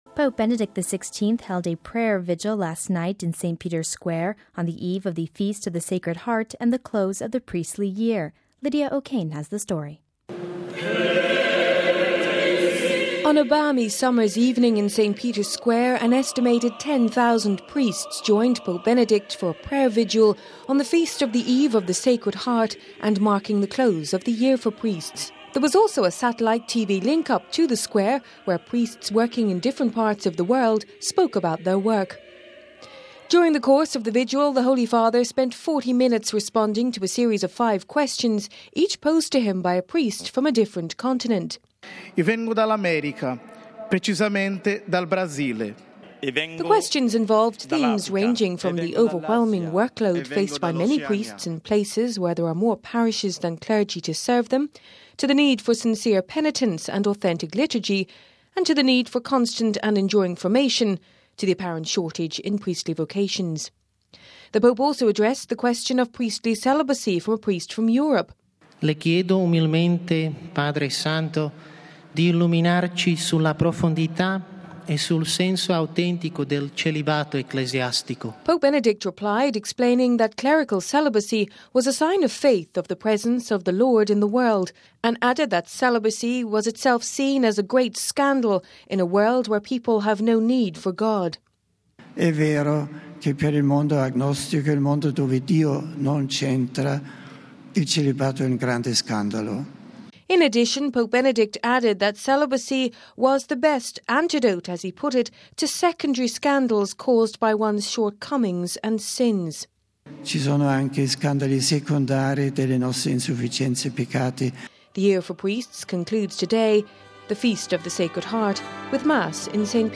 (11 June 10 - RV) Pope Benedict XVI held a prayer vigil last night in St. Peter’s Square, on the eve of the Feast of the Sacred Heart and the close of the Priestly Year. We have this report...